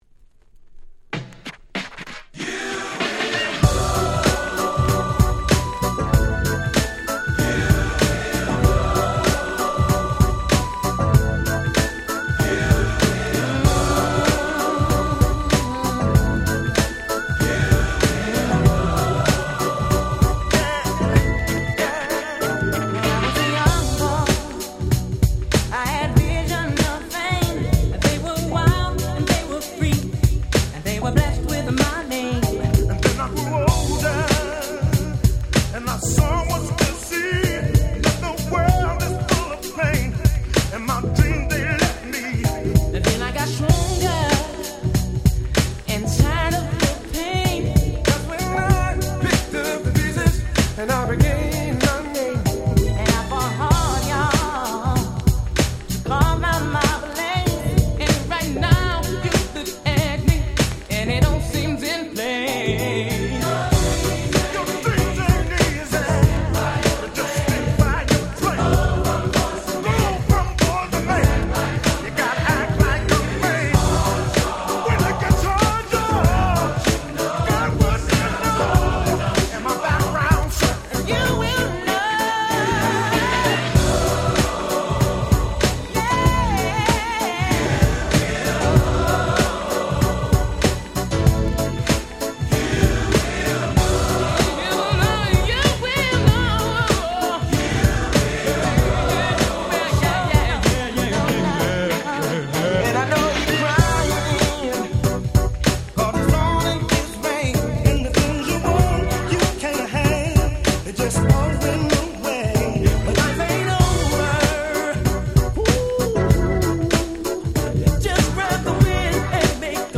95' Super Nice R&B !!